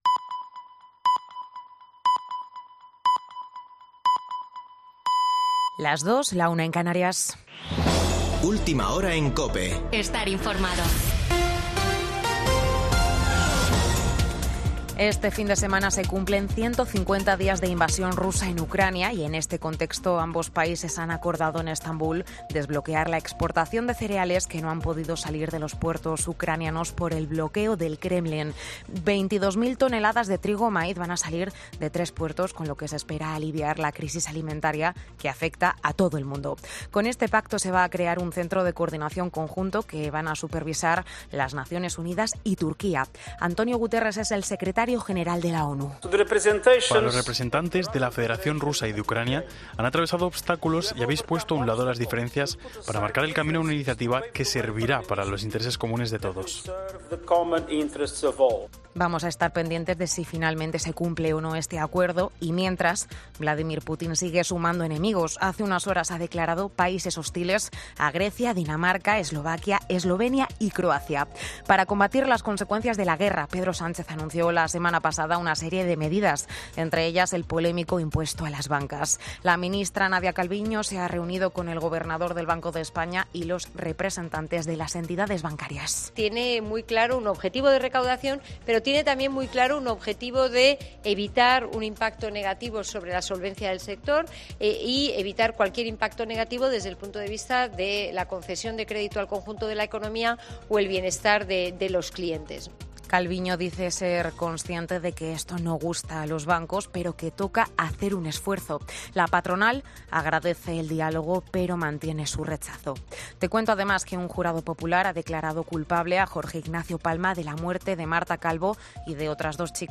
Boletín de noticias de COPE del 23 de julio de 2022 a las 02.00 horas